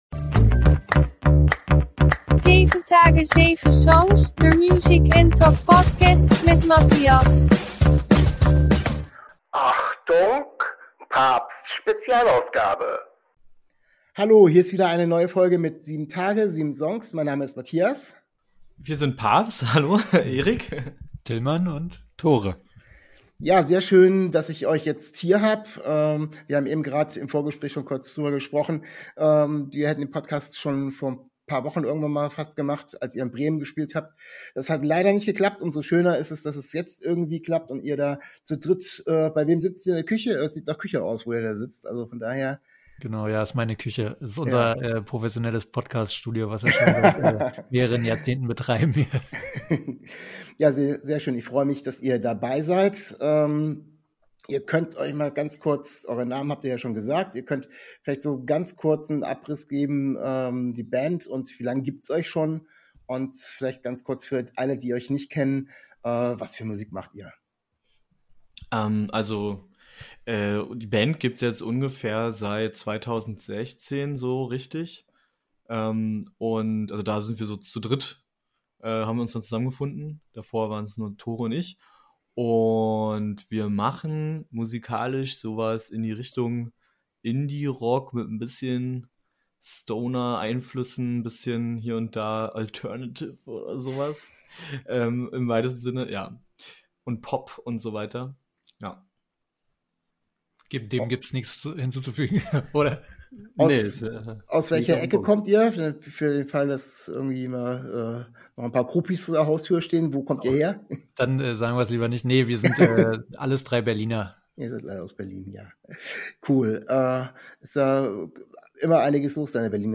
In dieser Folge sind die drei Jungs von PABST aus Berlin zu Gast. Wir reden ein wenig über die Bandgeschichte und die Entwicklung der Band und die Jungs stellen ihr gerade erschienenes Album vor-